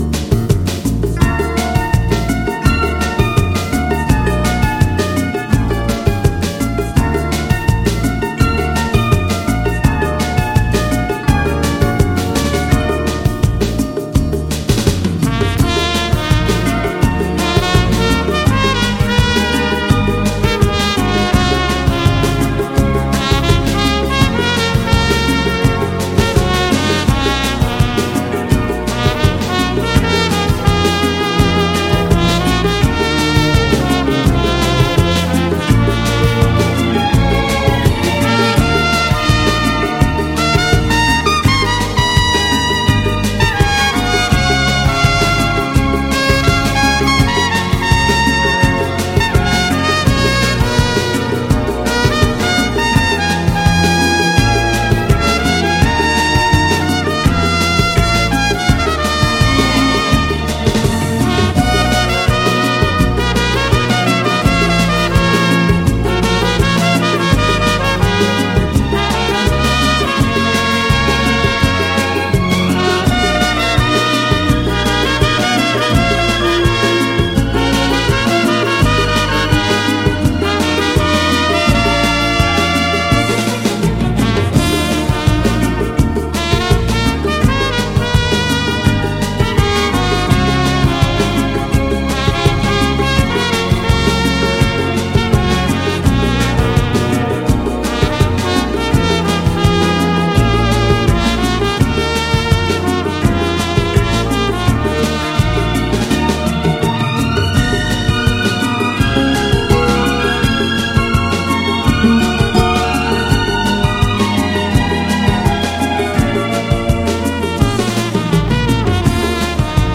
你听悠扬的小号，伴我们飞翔于白云间，清静与安逸与我们相伴。
磁带数字化